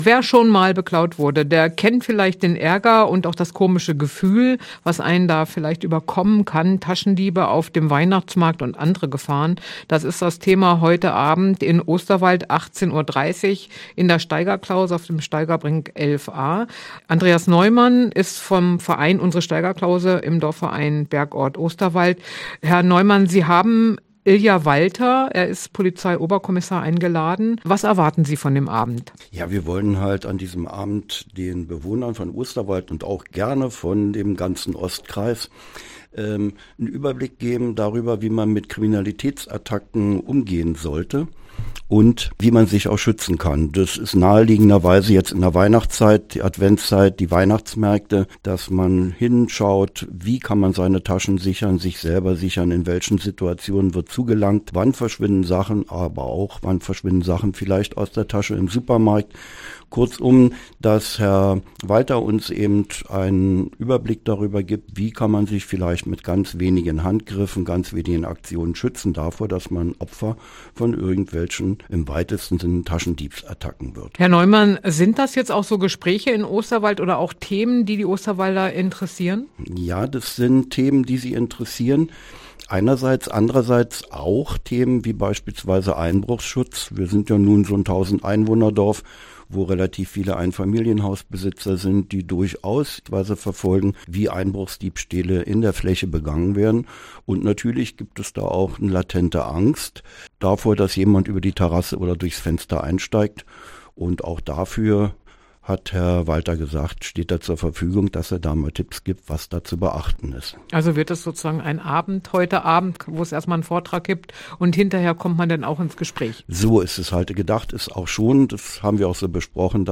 Vortrag: Taschendiebe auf dem Weihnachtsmarkt